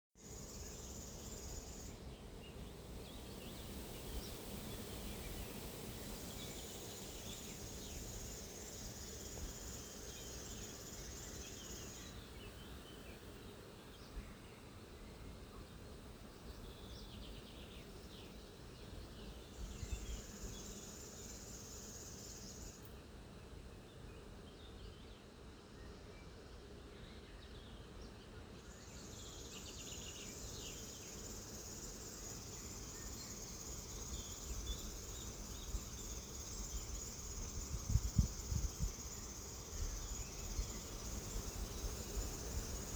Птицы -> Славковые ->
сверчок, Locustella naevia
Administratīvā teritorijaRīga
СтатусПоёт